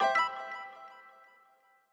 Longhorn 9X - Exclamation.wav